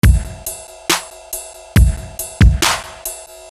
凯尔特人伊斯兰教的阿拉伯语弦乐
描述：阿拉伯弦乐部分循环
Tag: 153 bpm Dubstep Loops Strings Loops 1.06 MB wav Key : Unknown